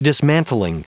Prononciation du mot dismantling en anglais (fichier audio)
Prononciation du mot : dismantling